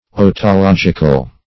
Otological \O`to*log"ic*al\, a. Of or pertaining to otology.